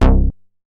MoogGrabThatA.WAV